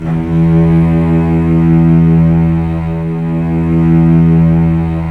Index of /90_sSampleCDs/Roland LCDP13 String Sections/STR_Symphonic/STR_Symph. %wh